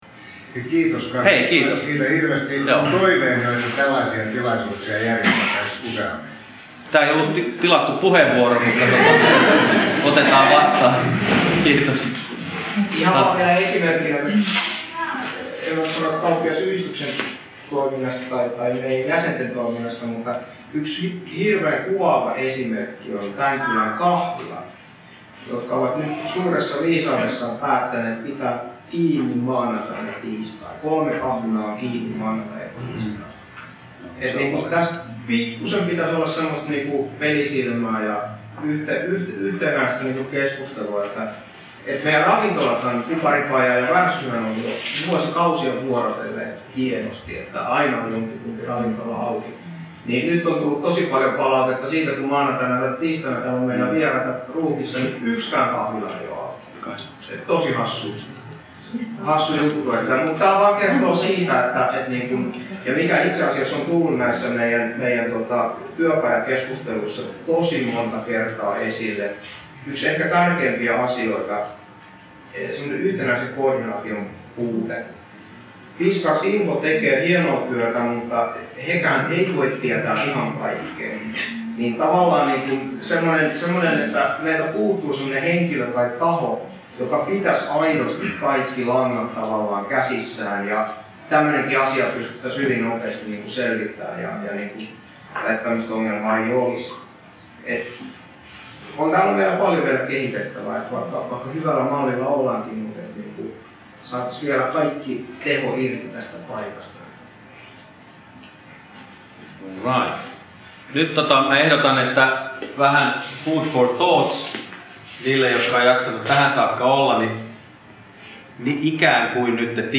Fiskars Oyj:n Kiinteistöliiketoiminnan kutsusta eri yhdistykset ja toimijat kokoontuivat keskustelemaan vuoteen 2015 liittyvistä tavoitteista.